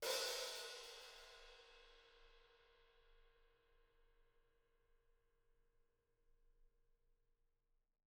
R_B Hi-Hat 10 - Room.wav